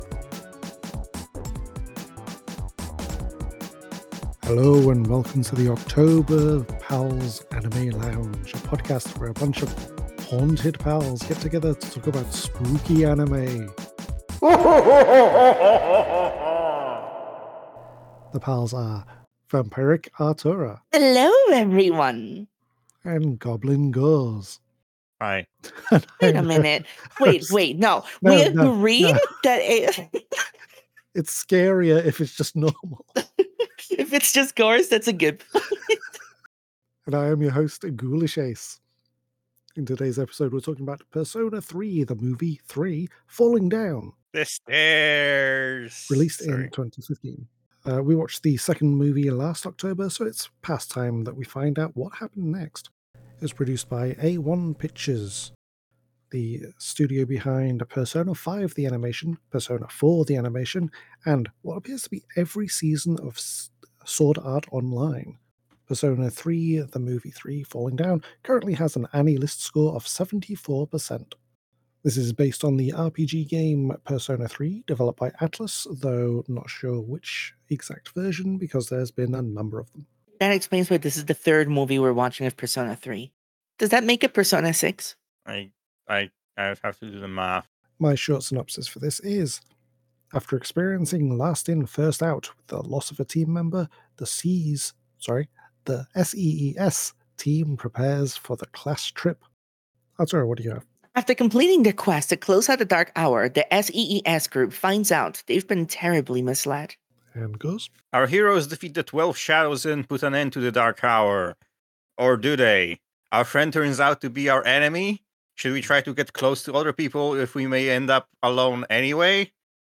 Welcome to the Pals Anime Lounge, a podcast where a bunch of pals get together to talk about anime!